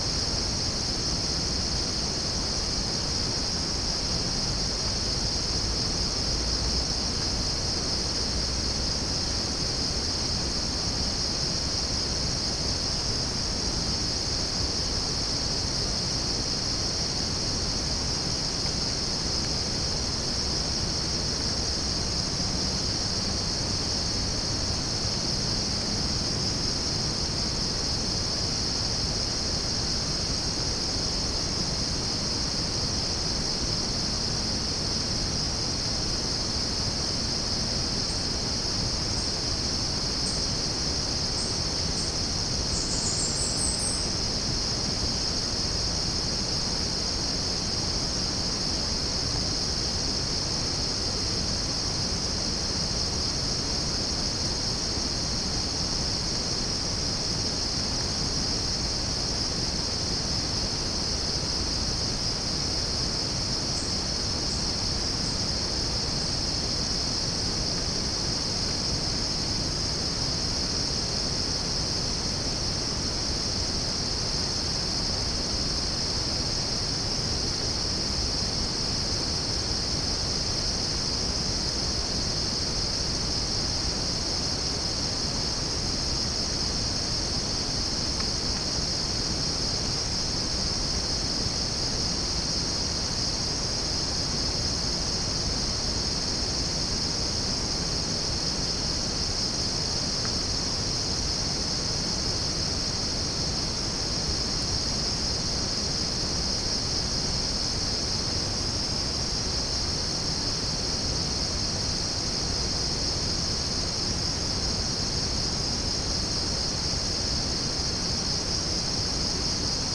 Chalcophaps indica
Pycnonotus goiavier
Halcyon smyrnensis
Pycnonotus aurigaster
Orthotomus ruficeps
Dicaeum trigonostigma